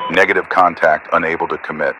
Added .ogg files for new radio messages
Radio-pilotWingmanEngageNoTarget4.ogg